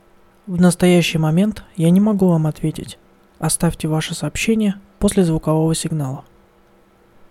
voicemail_intro.mp3